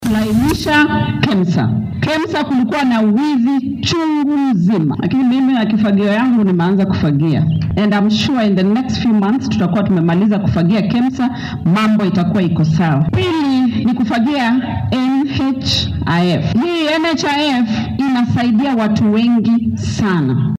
Arrimahan ayay wasiiraddu ka hadashay xilli ay shalay isbitaalka St. John’s Mission Hospital ay ka daahfurtay degmada Sirende ee deegaan baarlamaneedka Kiminini oo ka tirsan ismaamulka Trans Nzoia.